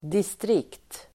Uttal: [distr'ik:t]